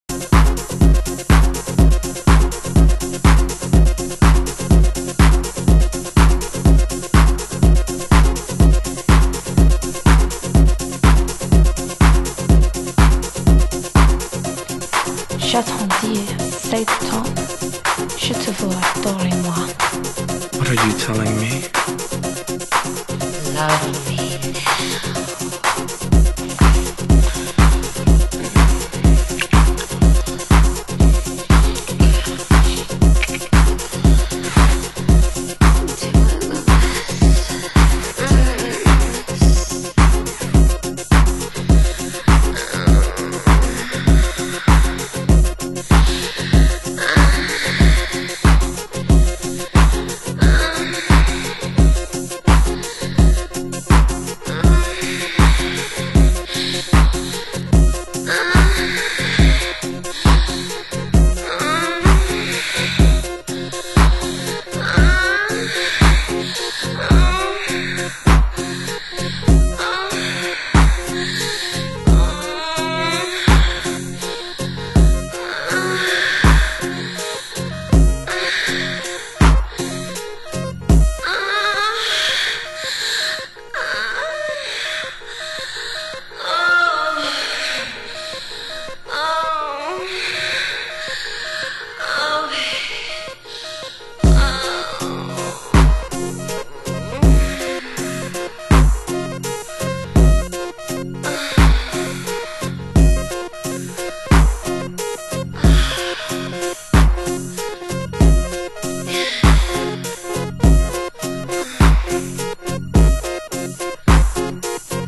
盤質：軽いスレ、小傷、少しチリパチノイズ有　　　ジャケ：スレ有